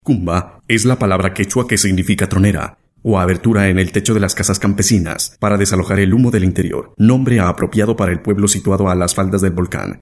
kolumbianisch
Sprechprobe: eLearning (Muttersprache):